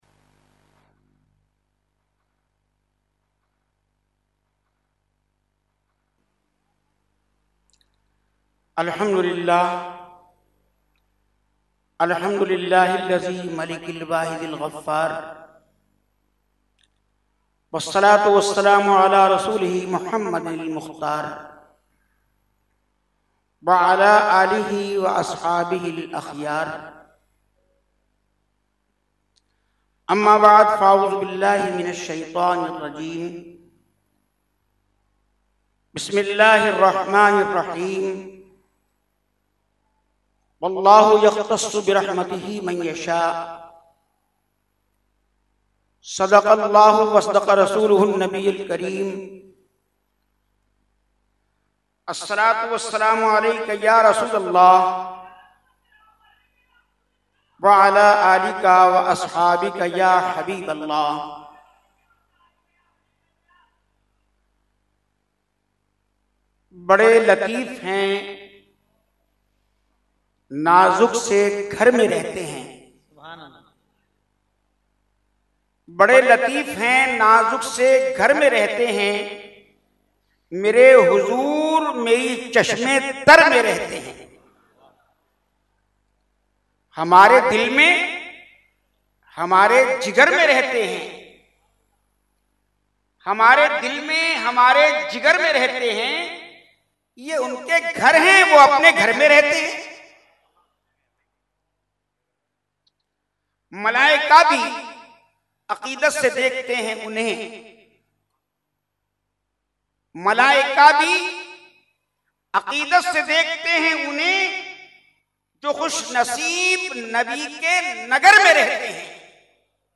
Category : Speech | Language : UrduEvent : Urs Makhdoome Samnani 2019